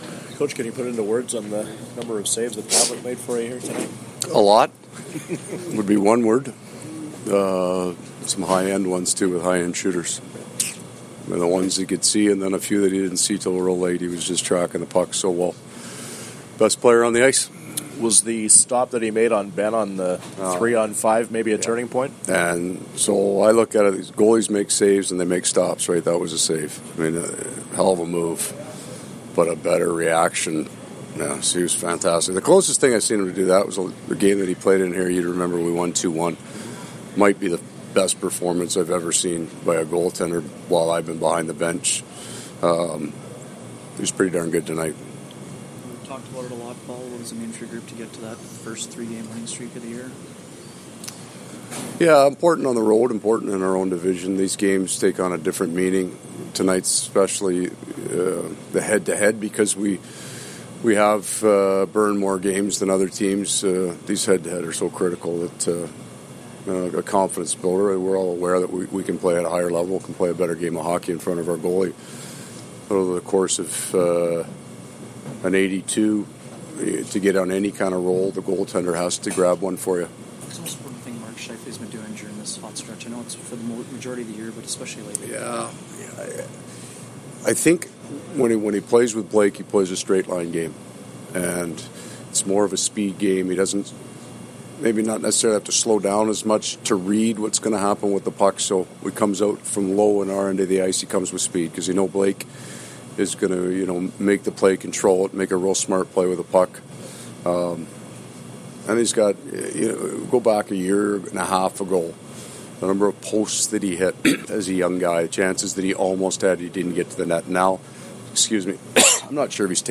Post-game from the Jets dressing room as well as from Coach Maurice.